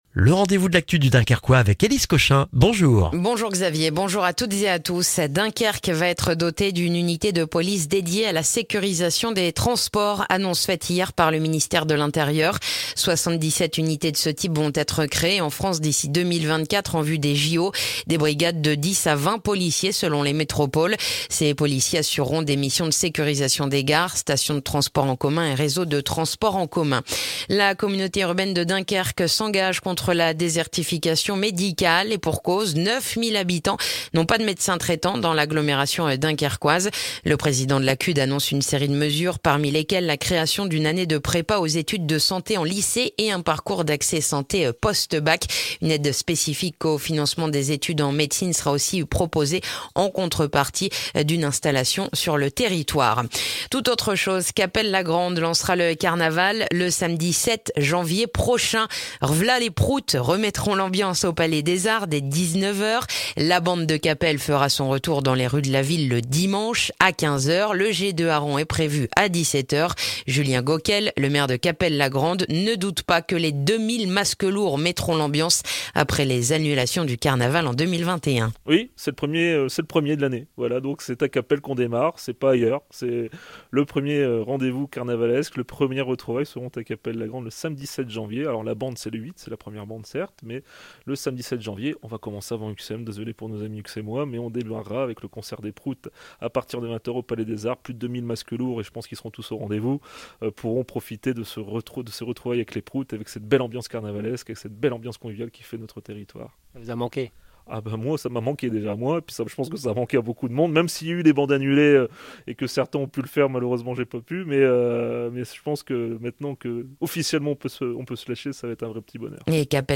Le journal du vendredi 25 novembre dans le dunkerquois